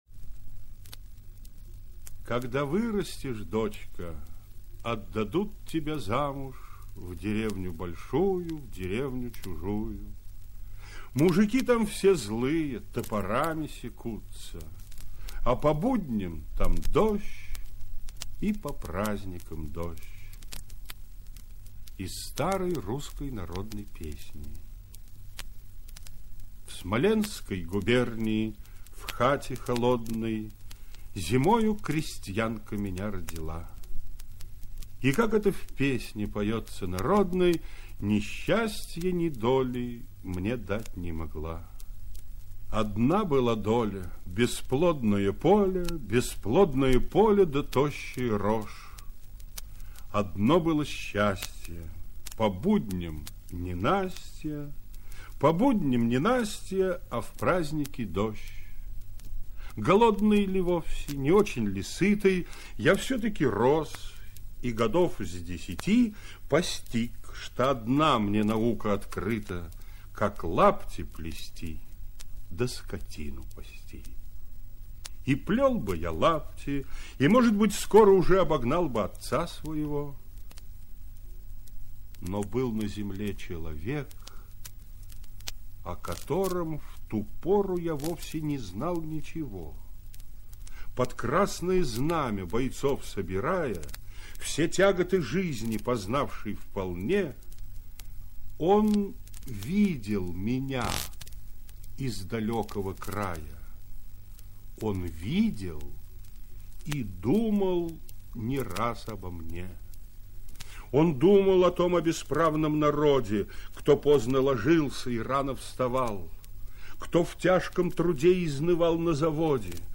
1. «Исаковский М – Дума о Ленине (чит. В.Хохряков)» /